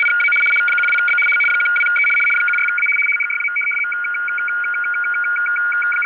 ЧМ